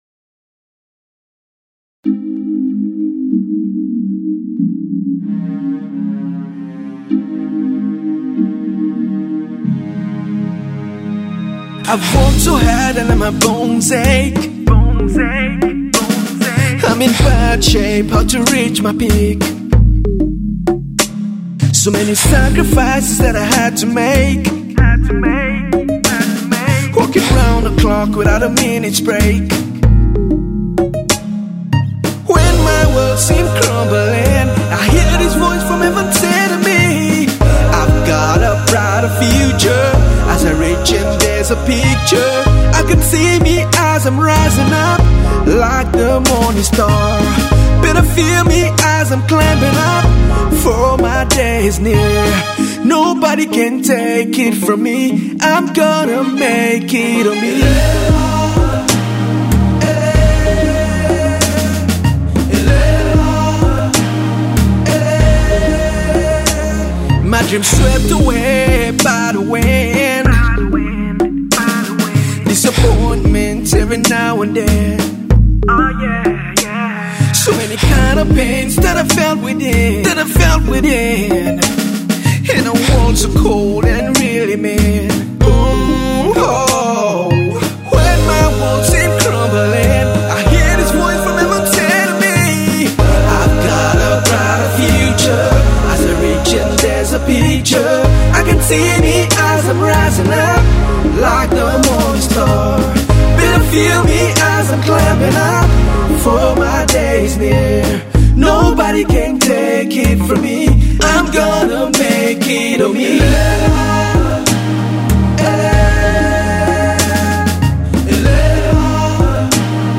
ballad song